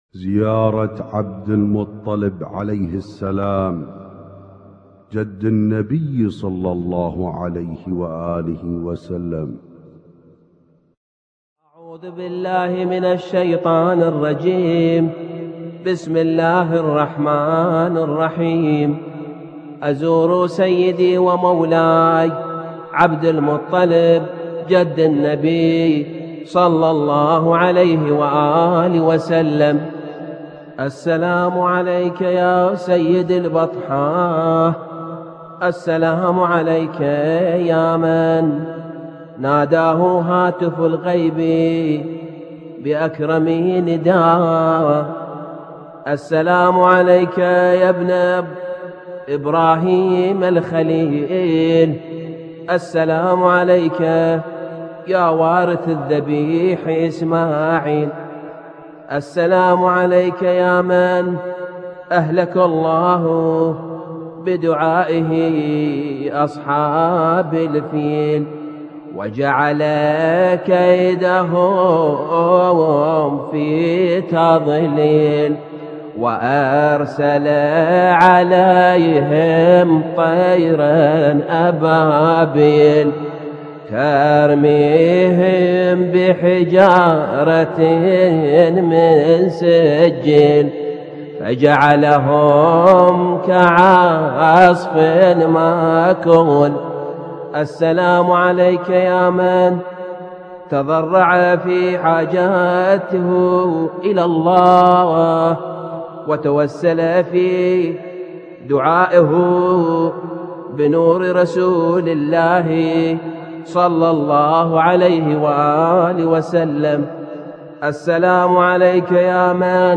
المـكتبة الصــوتيه >> الزيارات >> سلسلة النور